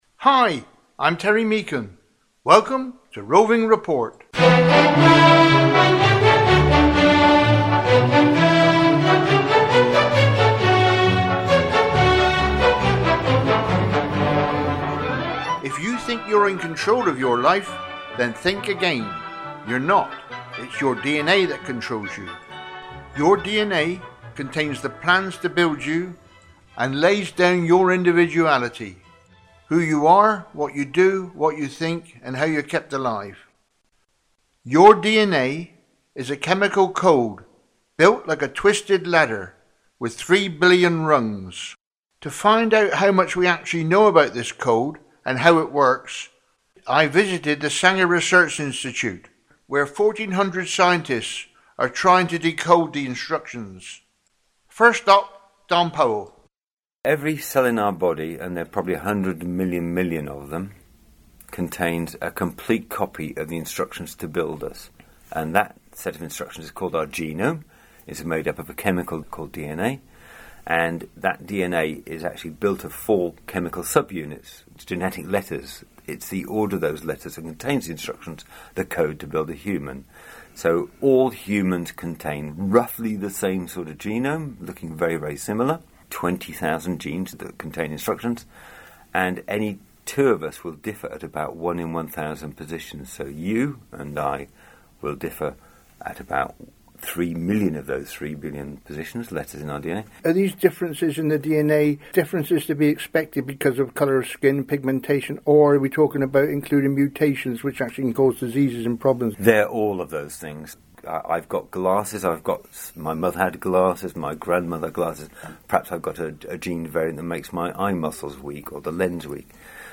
In this programme, we visit the Sanger Institute and question three scientists and ask what they are doing, how and why they are doing it and what it means us as we go about our daily business 🔬 More info on the Sanger Research Institute ← Back to all programmes Contact Producer →